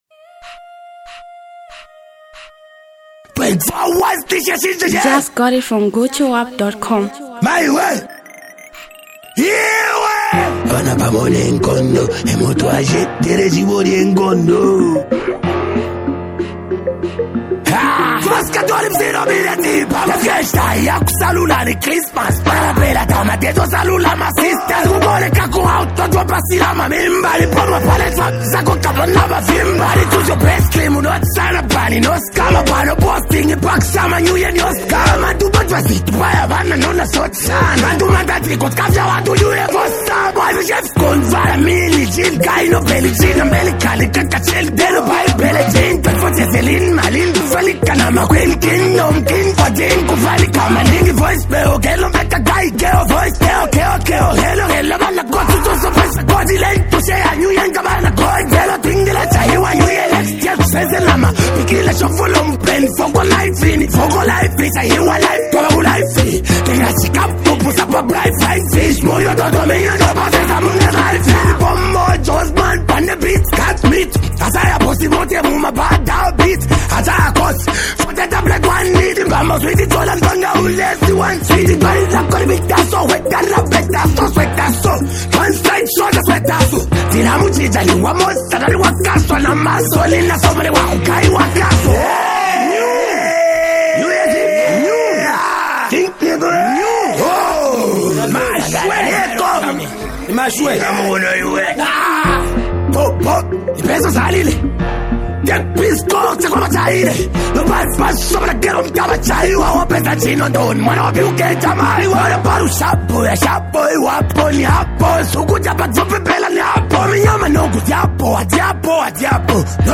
Zambian Mp3 Music